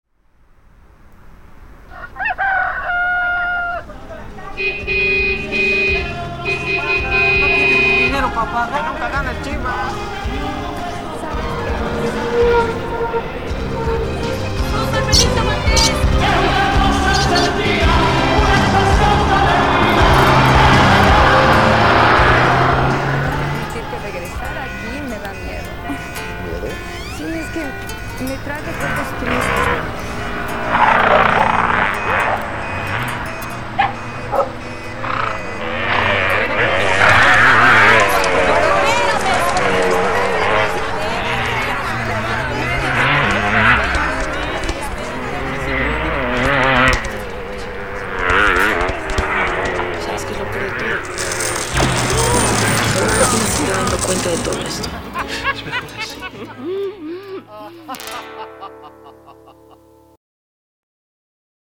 A soundscape inspired by my memories of living in the small town of Rota, in the southern tip of Spain.
And so time is compressed into one busy day (or minute), a busier one than you would perhaps experience had you been there, but the ambience keeps the sonic space away from the surreal.
So, if you know Spanish the dialogues (and accents) may seem somewhat strange, coming from soap operas and talk shows.
The ambience and certain background sounds (soccer ball kicking) are recorded directly into my computer via a long cord and a microphone (I lead it out the window where I recorded outside on my balcony.
However, I lost some of the tone that could only get picked up by a mike, the pickup having a brighter, much less woody and resonant sound.
Other sounds (especially animals and vehicles) were picked up by a thorough scanning of the internet.
In the final mix I place the sounds at odds with eachother at diverse places in the stereo axis - and most everything pans, at least subtly.
That is, each one came in and fluctuated, reaching it's peak at one point before it disappeared.